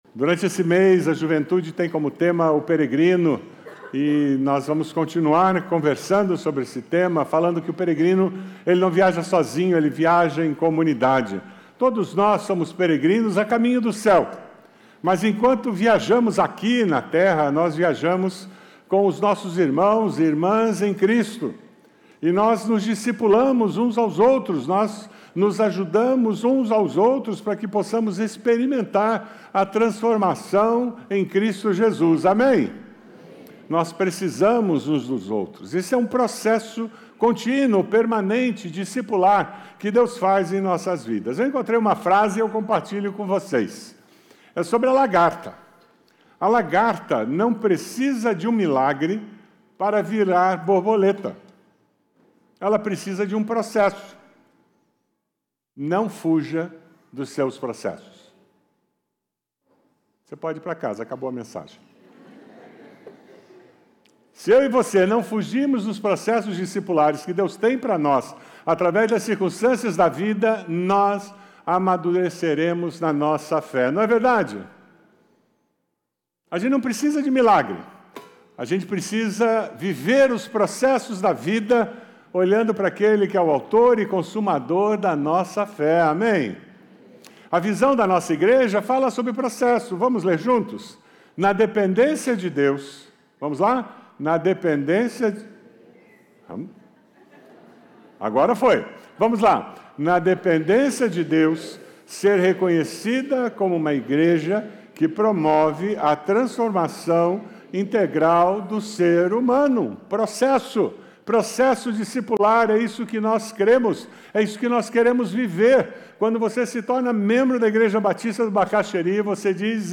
Igreja Batista do Bacacheri